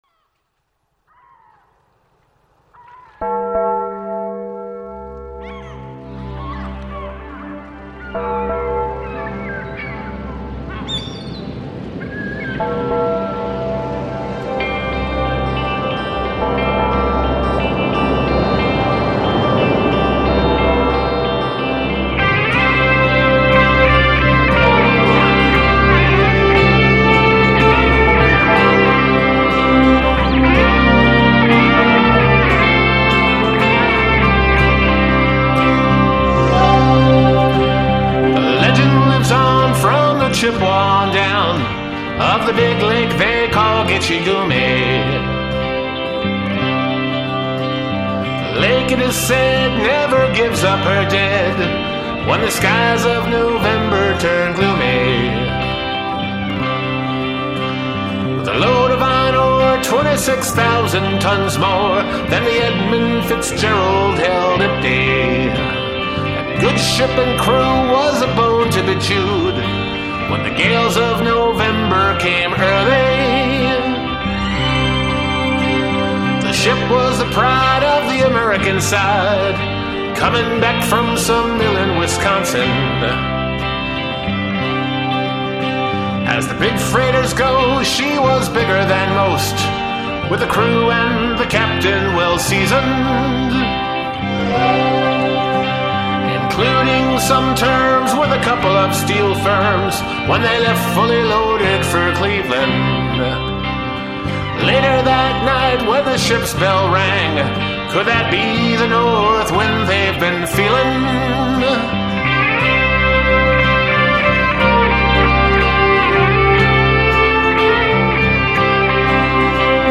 12 string tracks (two overdriven) punctuate this recording.